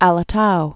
(ălə-tou, älə-)